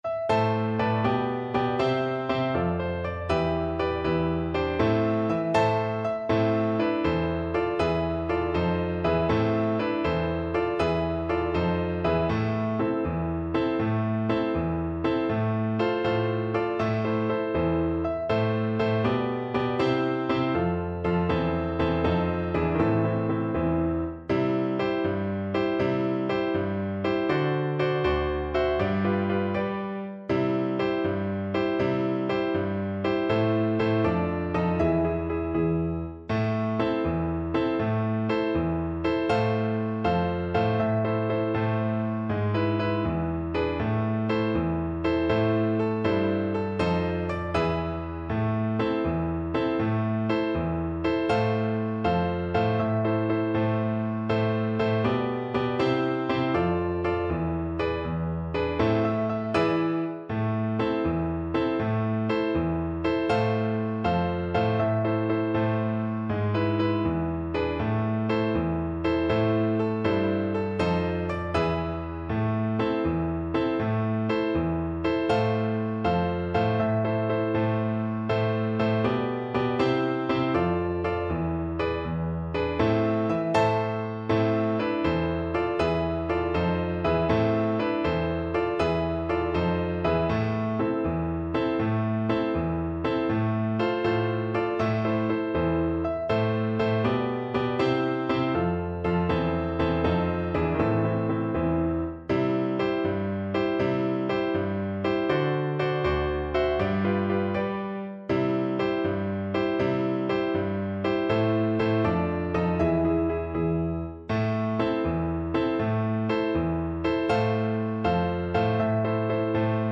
6/8 (View more 6/8 Music)
Brightly, but not too fast